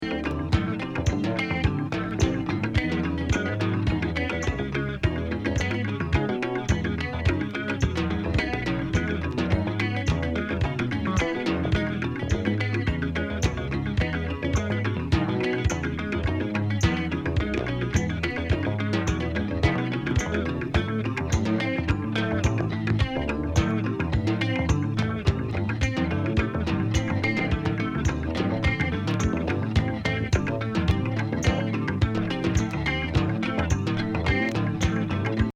フロアキラーチューンばっか、DJにもオススメ！インダストリアル・アンビエント！